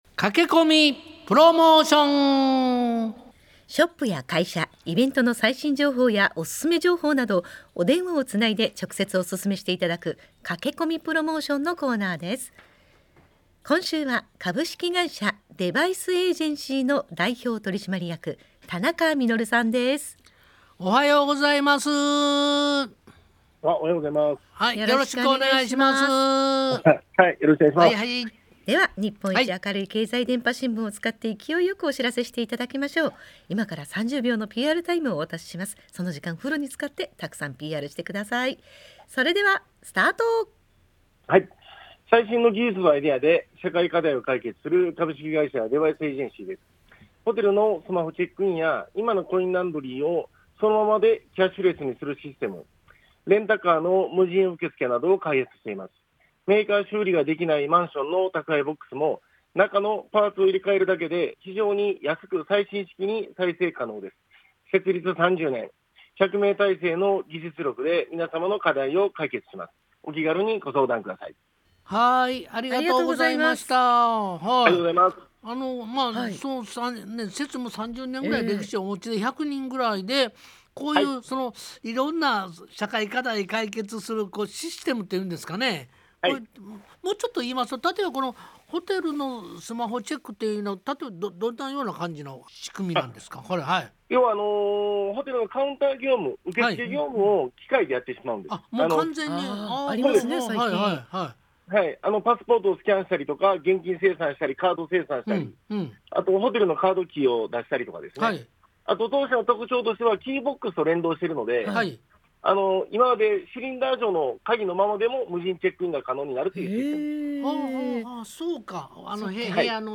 企業や店舗が電話出演にて「30秒PR」を行い、事業内容や取り組みを紹介します。